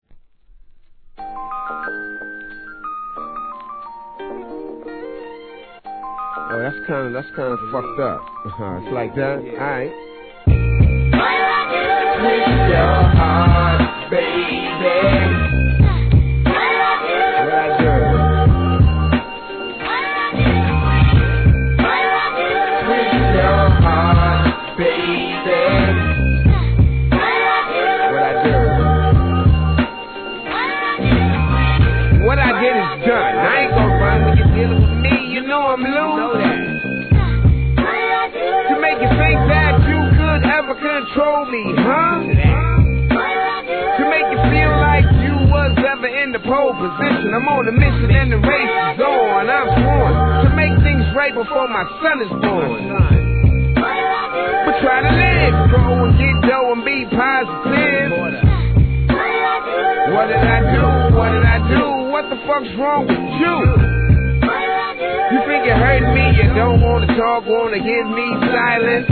HIP HOP/R&B
女性コーラスのLOOPが胸キュンな